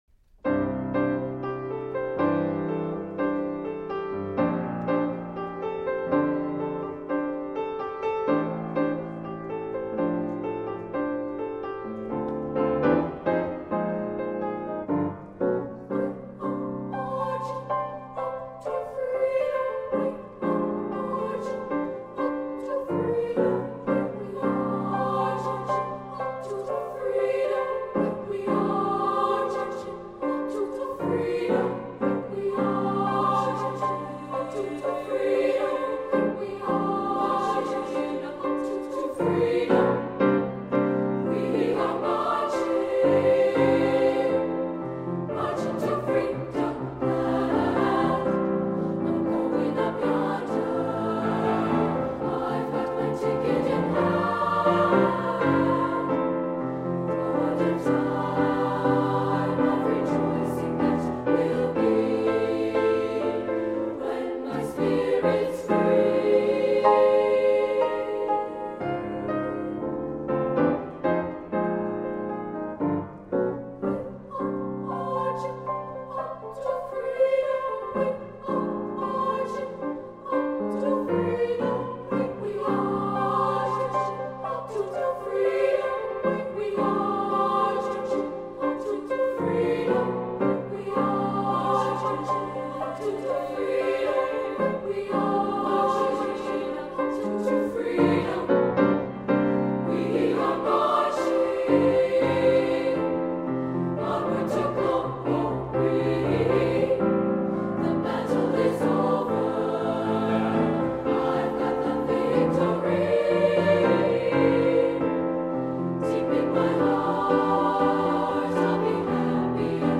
Voicing: SSA